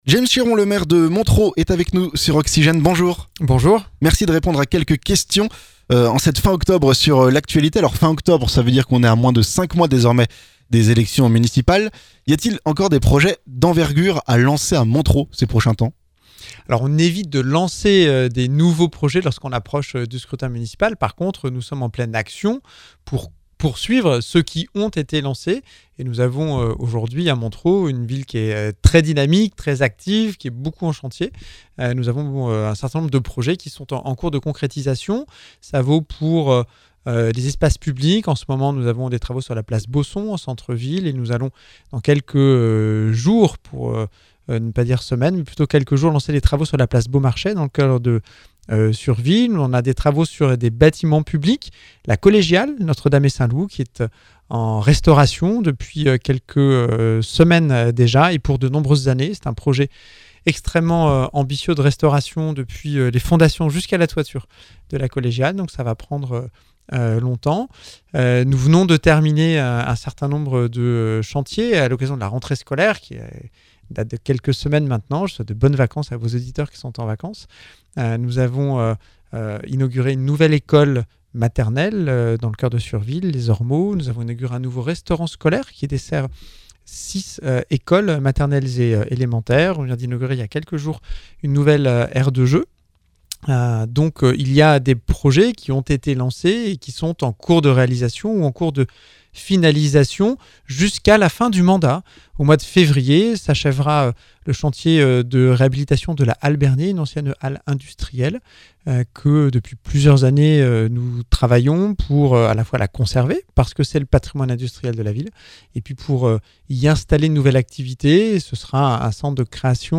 Notre entretien intégral avec le maire de Montereau, et Vice-Président du conseil régional d'Ile-de-France, James Chéron.